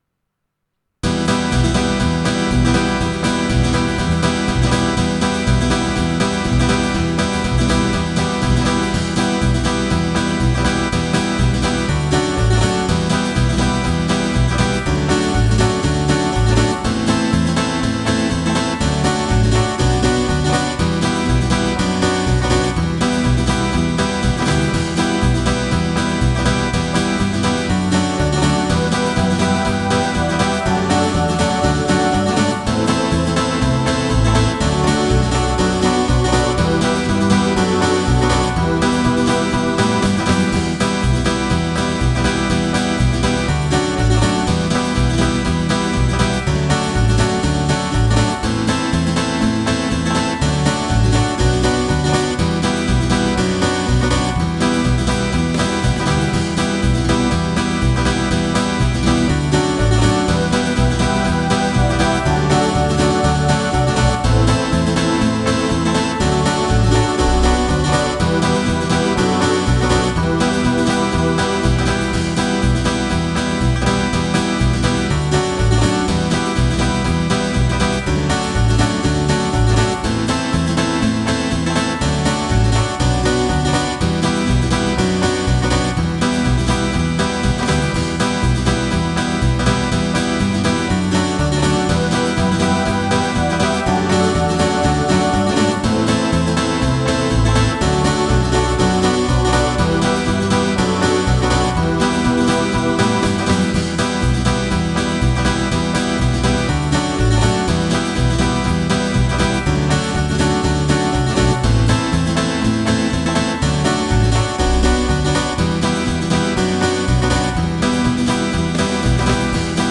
• Le fichier son, SANS la mélodie ==>